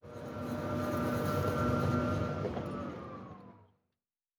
Golf Cart Distant.wav